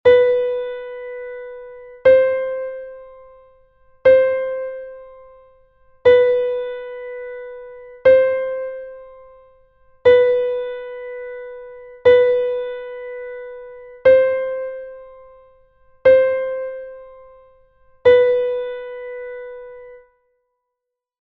Exercise 6: high B-C diatonic semitone exercise.
ejercicio_semitono_diatonico_si-do.mp3